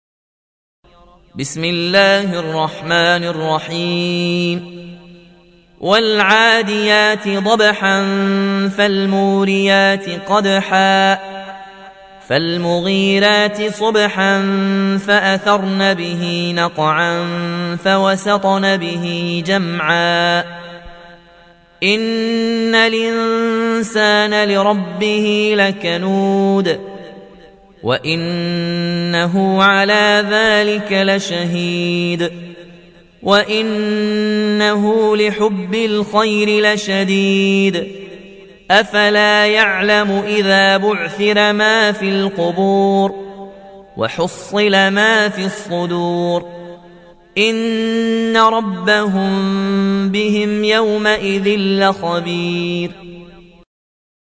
تحميل سورة العاديات mp3 بصوت ياسين الجزائري برواية ورش عن نافع, تحميل استماع القرآن الكريم على الجوال mp3 كاملا بروابط مباشرة وسريعة